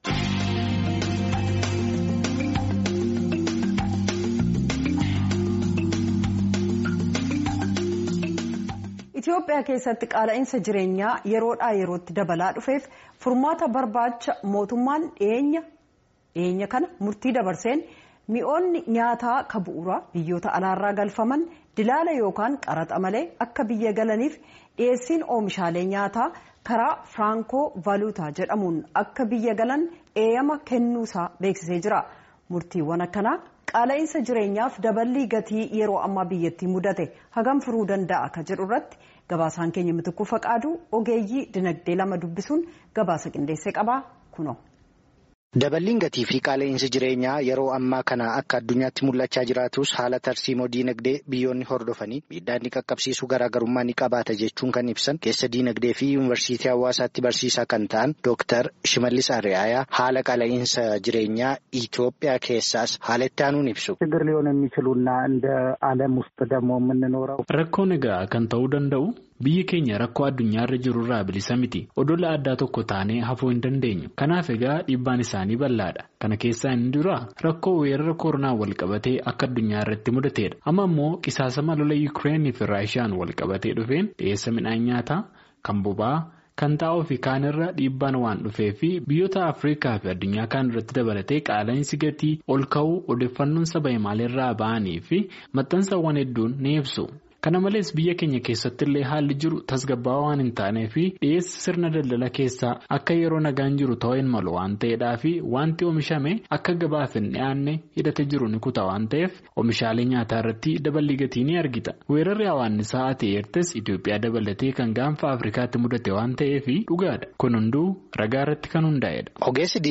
Gabaasa Ogeessota Dinagdee